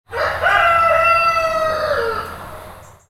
Natural Rooster Crow In The Distance – Village Atmosphere
Animal Sounds / Chicken Sounds / Sound Effects
Natural-rooster-crow-in-the-distance-village-atmosphere.mp3